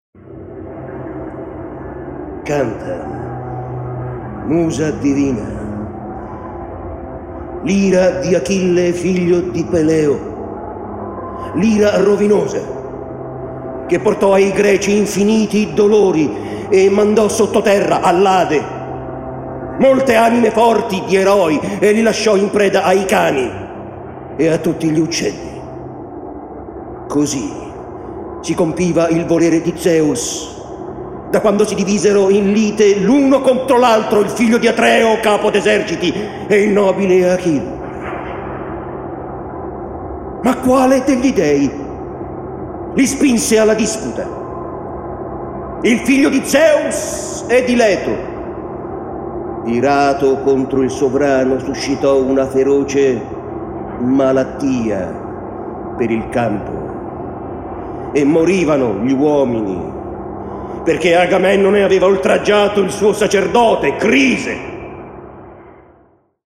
letto da Massimo Popolizio
Nel 2007 al Foro di Traiano, per dieci serate un manipolo di grandi attori ha dato voce ai più bei canti del poema omerico. Di quelle indimenticabili letture, in cui risuonano l’ira di Achille e i dolenti giorni della guerra tra Greci e Troiani, si ripropone qui la registrazione live effettuata a Roma.